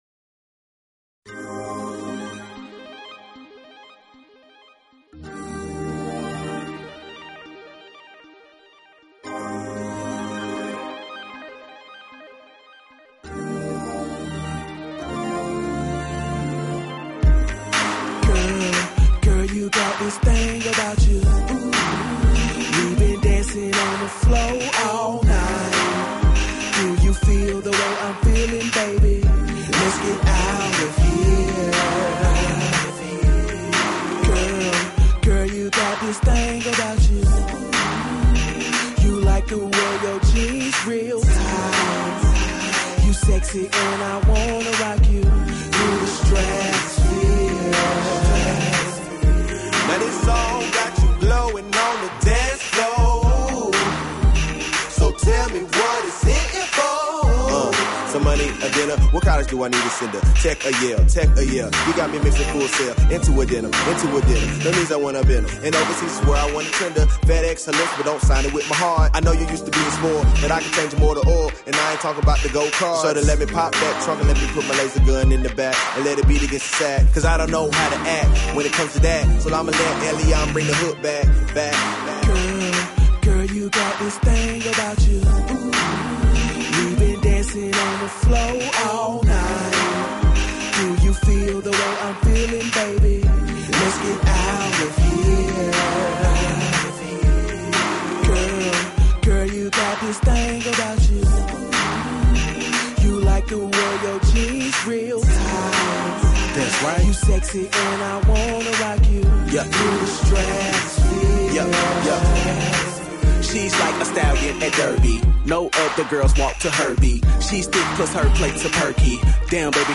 Talk Show Episode, Audio Podcast, Todays_Entrepreneurs and Courtesy of BBS Radio on , show guests , about , categorized as
In addition to daily guests, TE spins the hottest indie musical artists and laughs along to the funniest comedians every day.